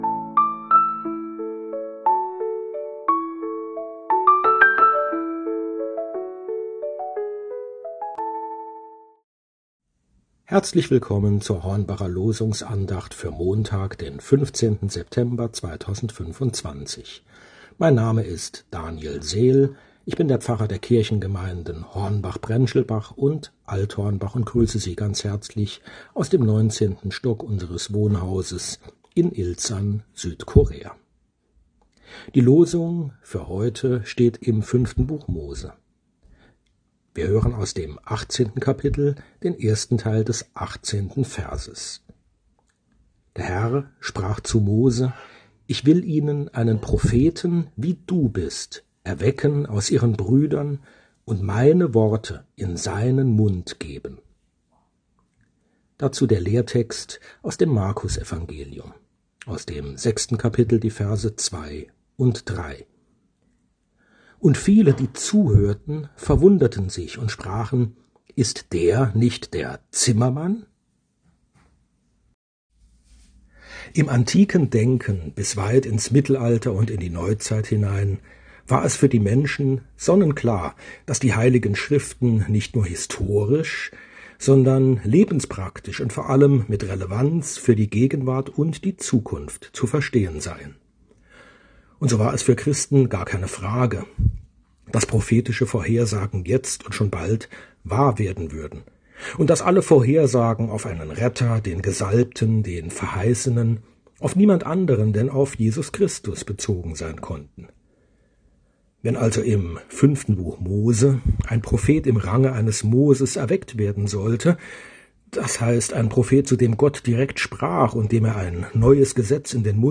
Losungsandacht für Montag, 15.09.2025